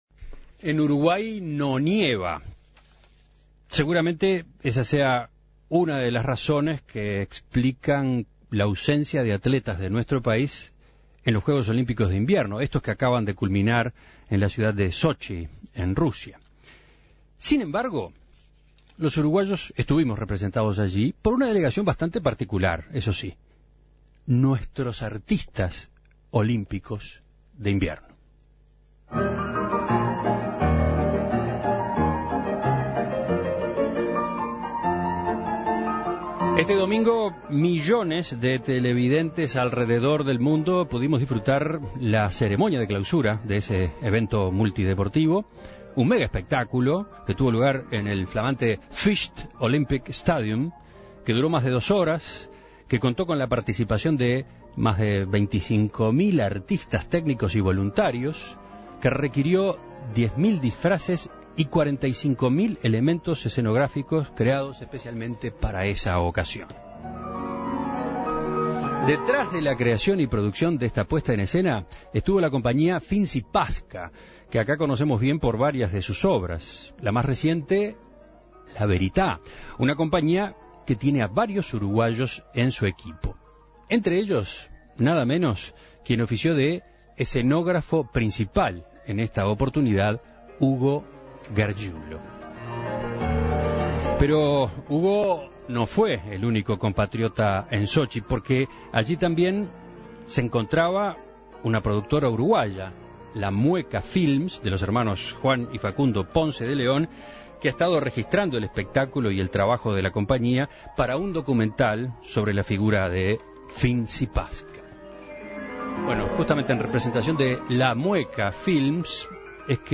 En Perspectiva conversó con ambos a propósito de esta experiencia, cómo dieron con la organización de Sochi y lo que supuso para ellos trabajar allí.